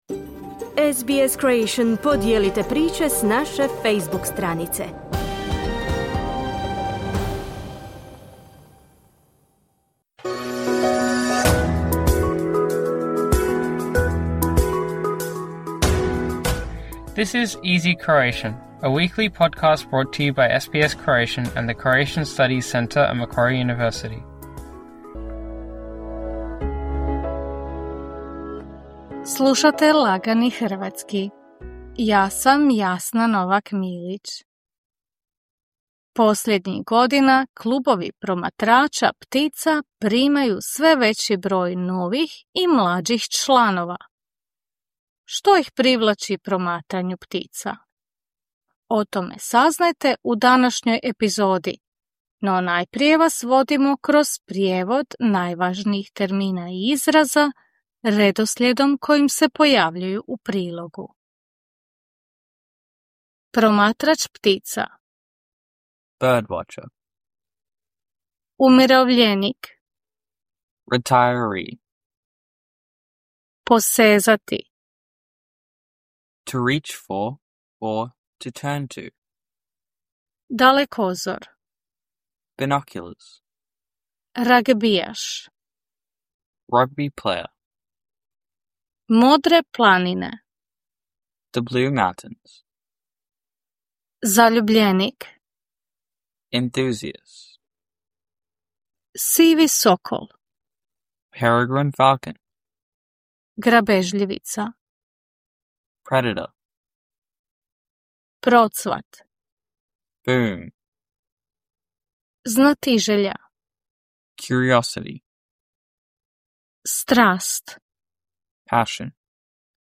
“Easy Croatian” is intended for those who want to brush up on their Croatian. News is written in simpler and shorter sentences and read at a slower pace. Before we move on to the feature, you will hear some of the more complex vocabulary and expressions, followed by their English translations.